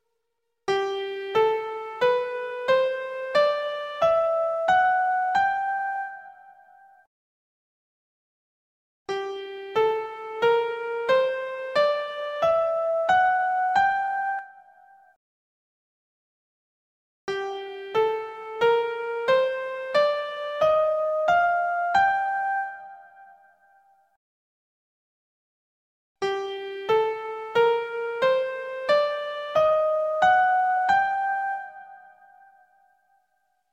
Part 3. Major/Minor Scales
One each Major, Natural Minor, Harmonic Minor, Melodic Minor
et4-scales.mp3